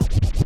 scratch13.wav